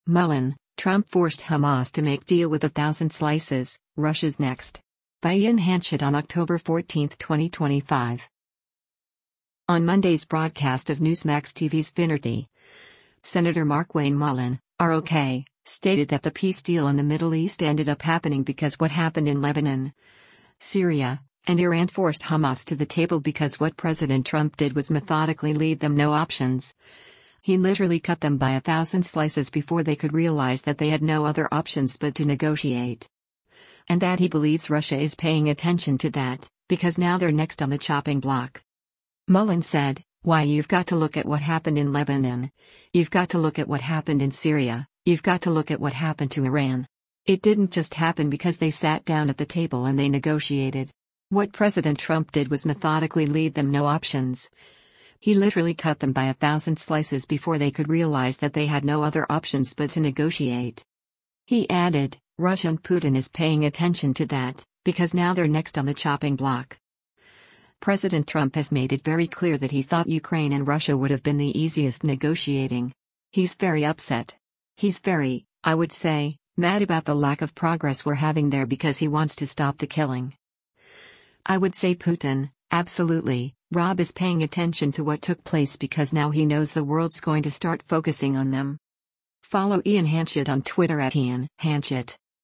On Monday’s broadcast of Newsmax TV’s “Finnerty,” Sen. Markwayne Mullin (R-OK) stated that the peace deal in the Middle East ended up happening because what happened in Lebanon, Syria, and Iran forced Hamas to the table because “What President Trump did was methodically leave them no options, he literally cut them by a thousand slices before they could realize that they had no other options but to negotiate.” And that he believes Russia “is paying attention to that, because now they’re next on the chopping block.”